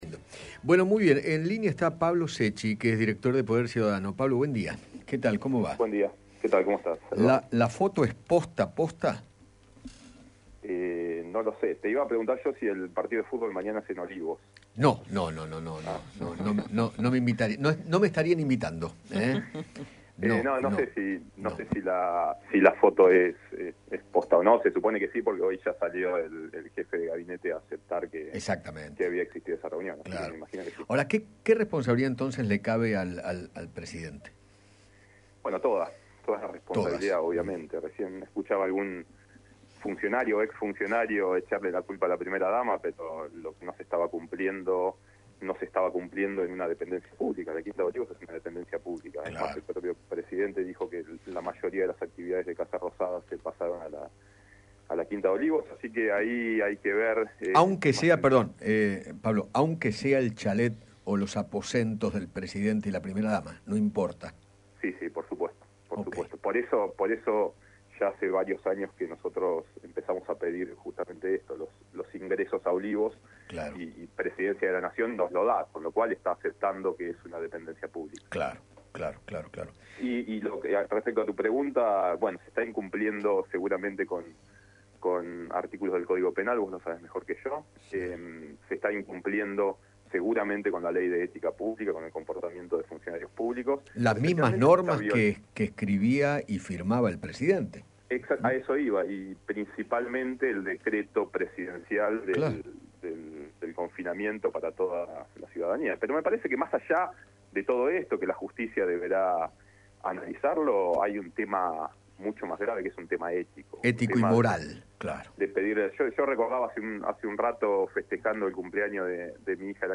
conversó con Eduardo Feinmann sobre la polémica foto en Olivos, las visitas que se dieron allí y aseguró que “al Presidente le cabe toda la responsabilidad”.